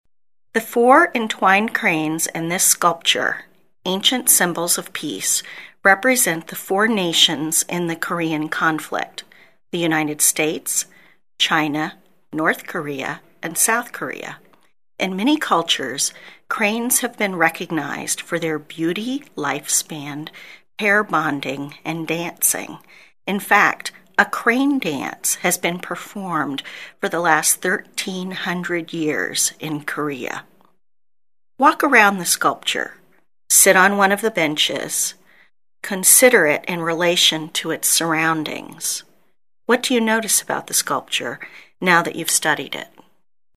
Audio Tour – Ear for Art